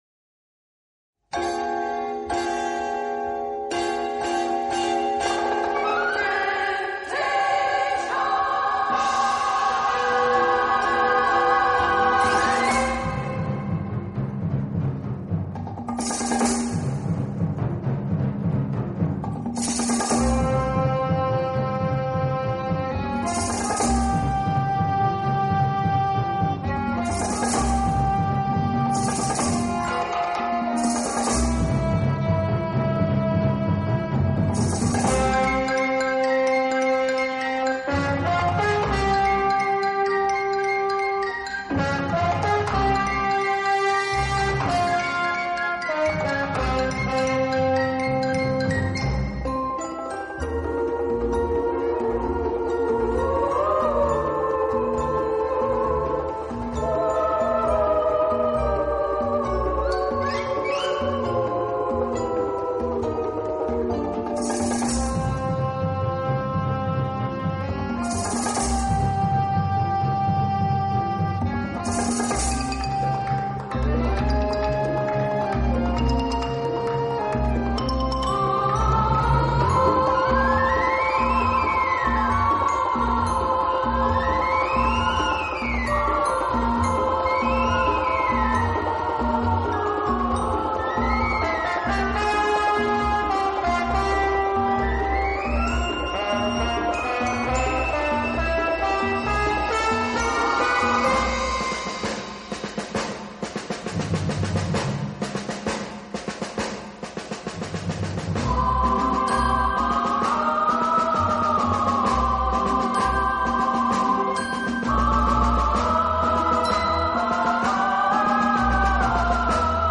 演奏以轻音乐和舞曲为主。